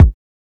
• 2000s Reverb Kick Sound F Key 25.wav
Royality free kick single hit tuned to the F note. Loudest frequency: 145Hz